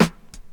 • Snare Drum E Key 116.wav
Royality free acoustic snare tuned to the E note. Loudest frequency: 1076Hz
snare-drum-e-key-116-Y9o.wav